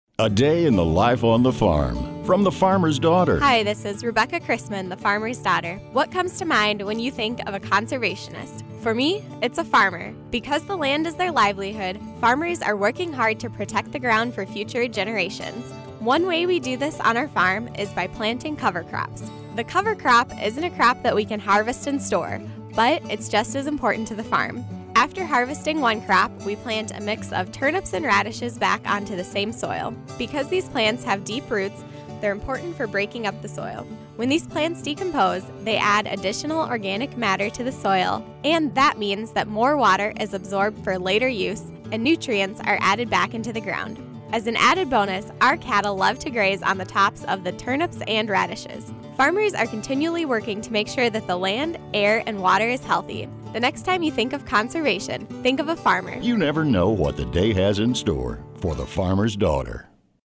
Listen to this week's radio segment here!